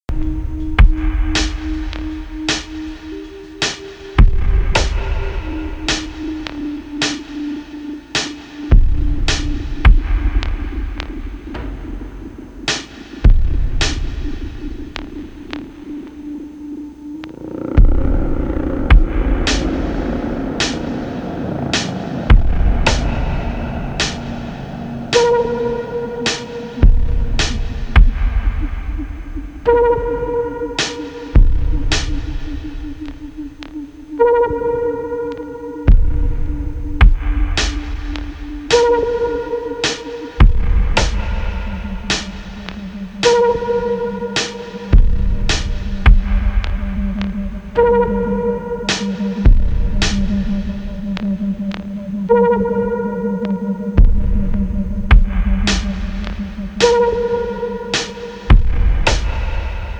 Electro Techno Wave